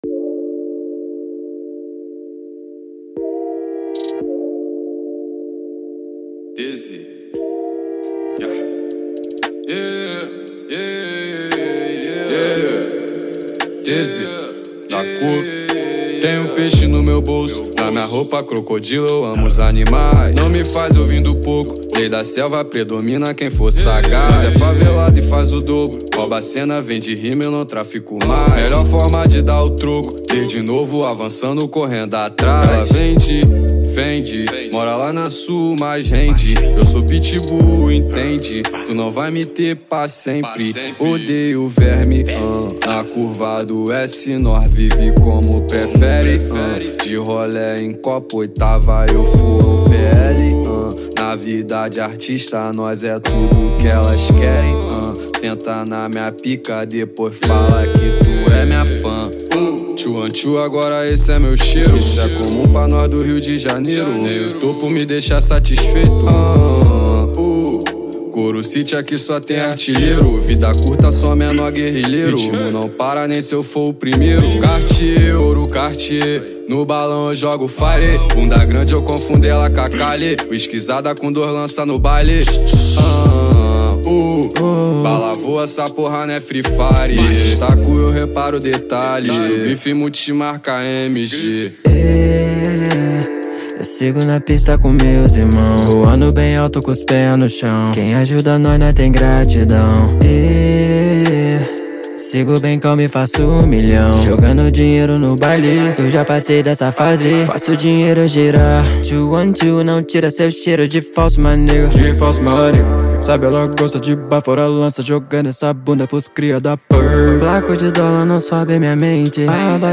2024-07-23 14:35:19 Gênero: Trap Views